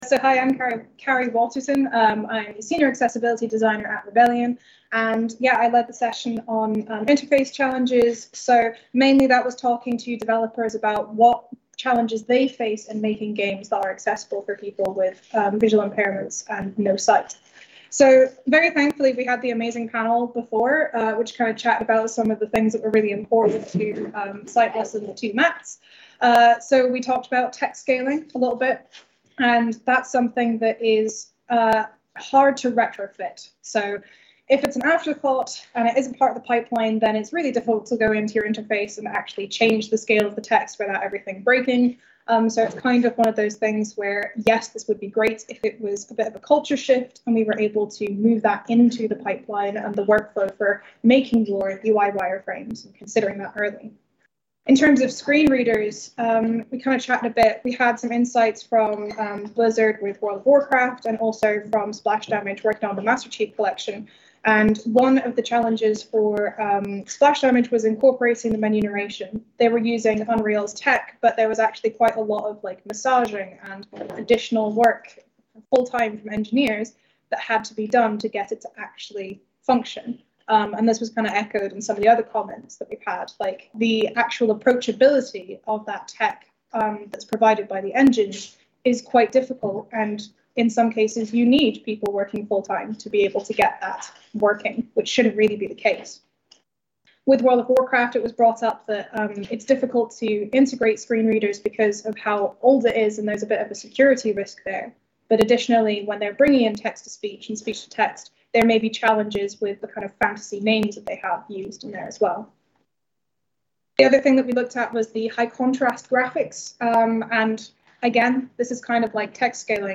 Accessible Gaming Symposium - Breakout Session 4 Presentation - Infrastructure Challenges for Designing Accessible Gaming Experience